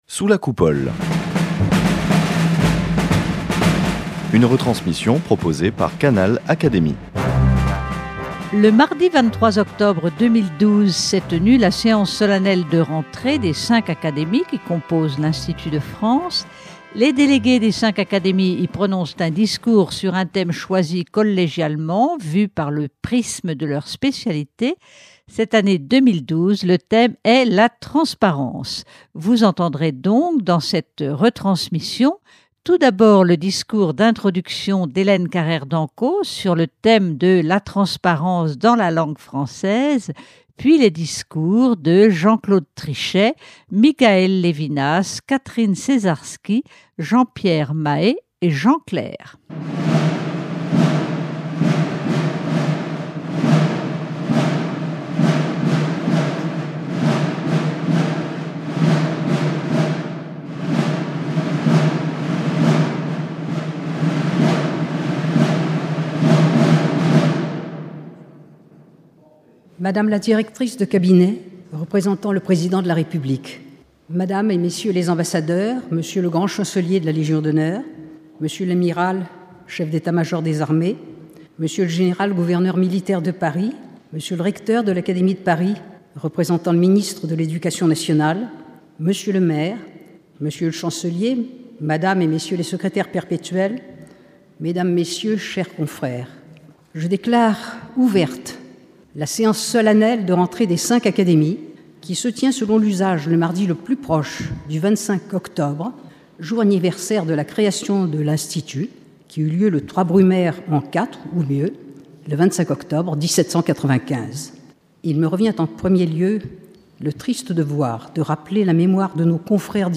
Le mardi 23 octobre 2012 s’est tenue la séance solennelle de rentrée des cinq Académies qui composent l’Institut de France.
Les délégués des cinq Académies y prononcent un discours sur un thème choisi collégialement, vu par le prisme de leur spécialité. Cette année 2012, le thème est « La transparence ».